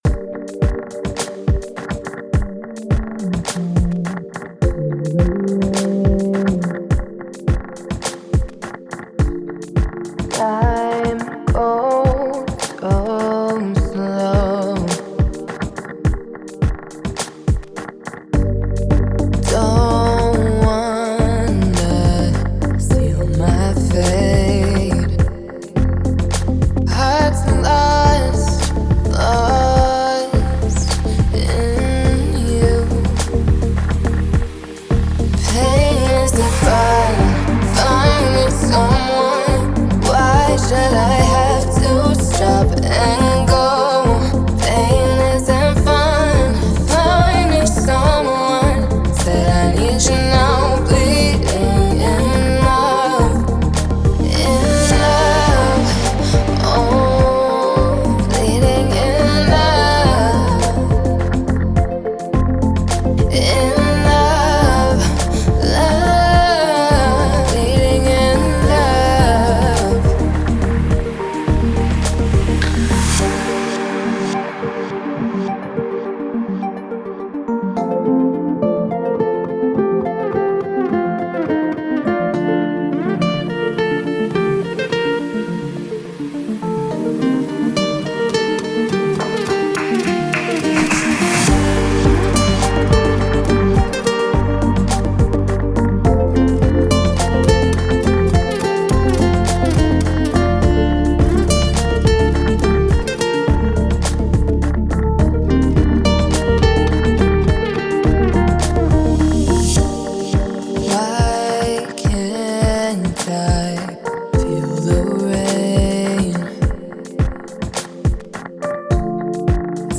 романтическая поп-песня